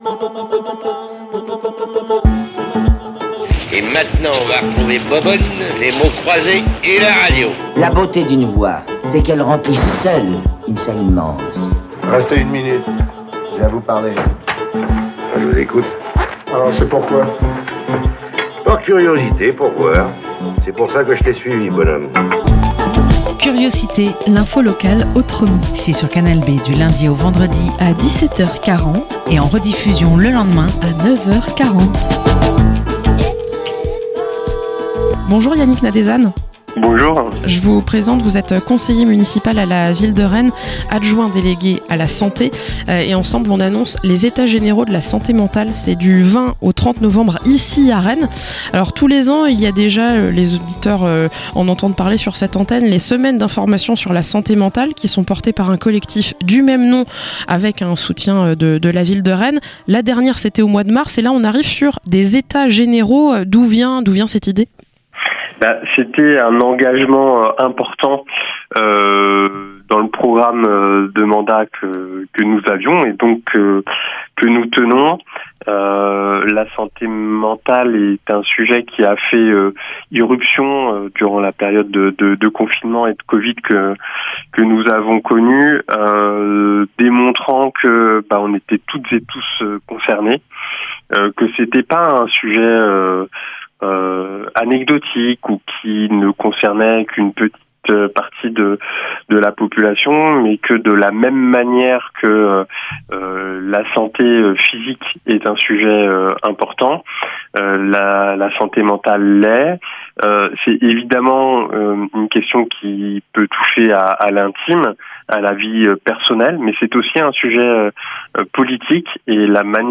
- Interview de Yannick Nadesan, conseiller municipal adjoint délégué à la santé, pour annoncer les Etats généraux en santé mentale.